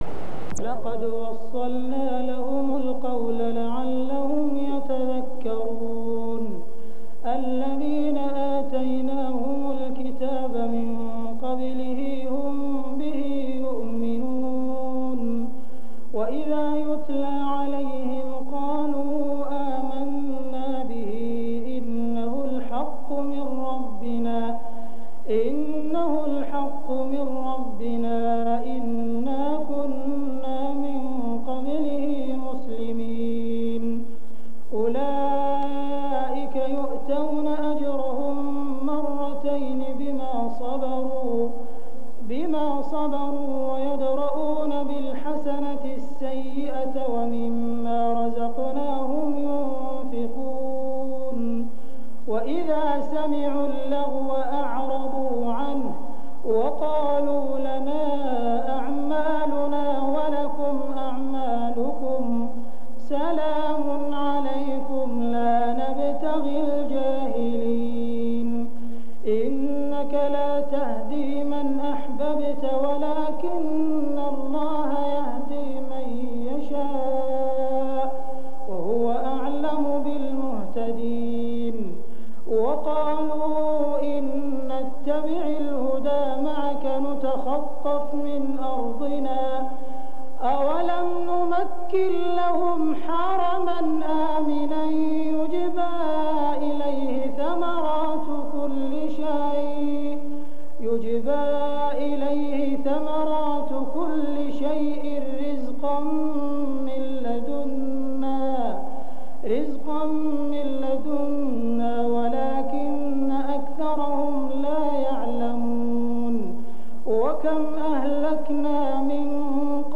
تراويح الحرم المكي عام 1406 🕋